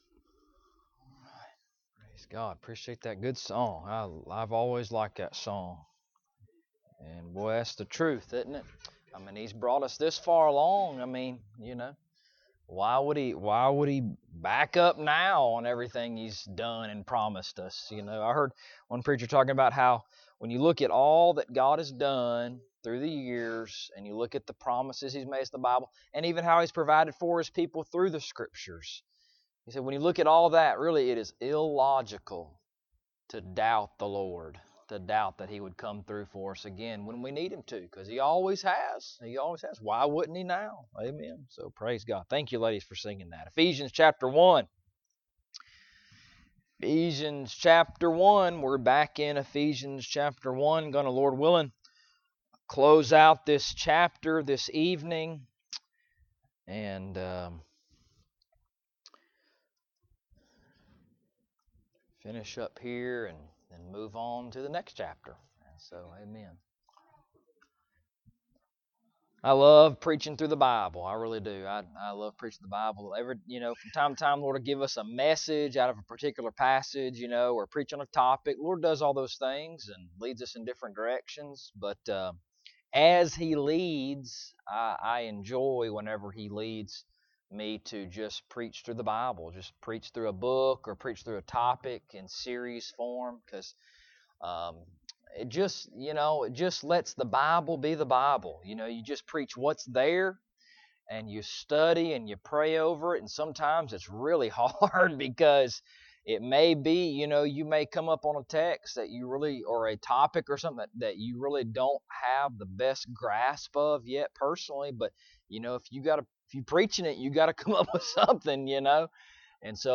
Ephesians Passage: Ephesians 1:15-23 Service Type: Sunday Evening Topics